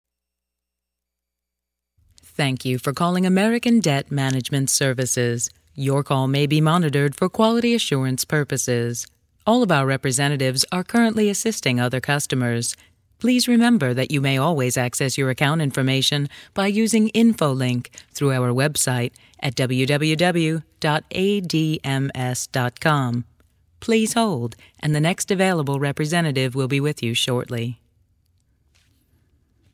EN US CL IVR 01 IVR/Phone systems Female English (American)